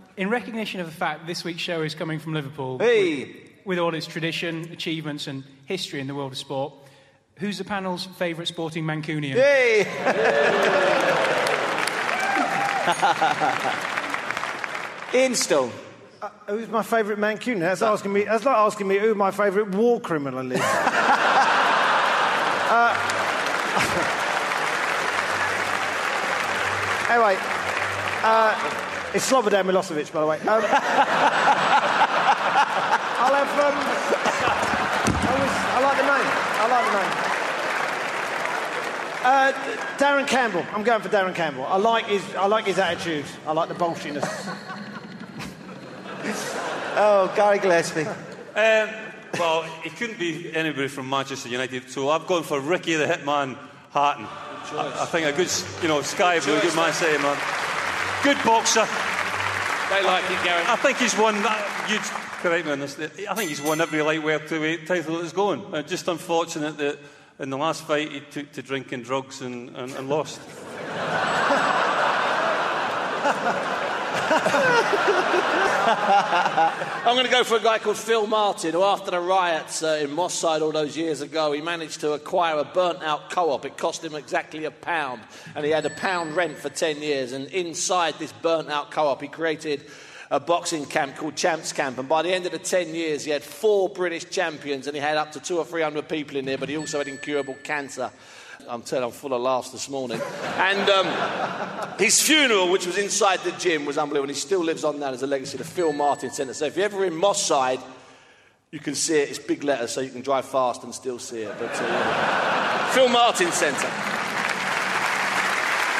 Ian Stone FT Live in Liverpool